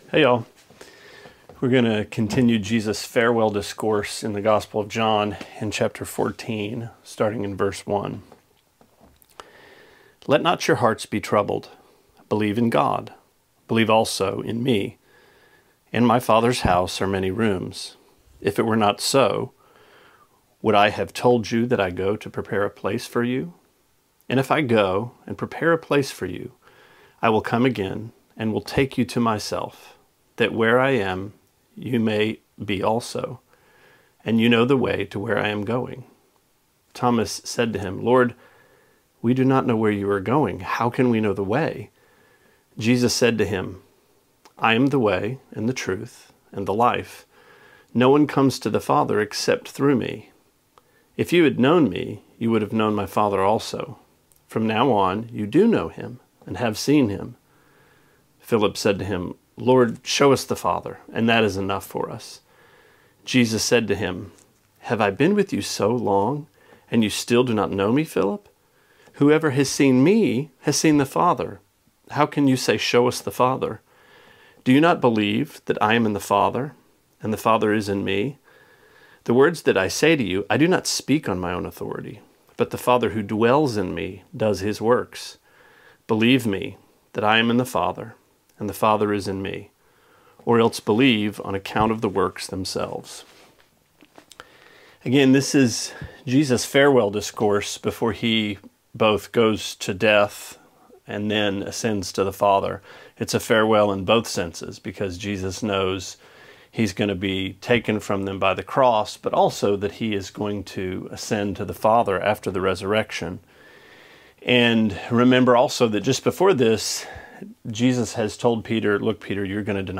Sermonette 5/20: John 14:1-11: How to Get Home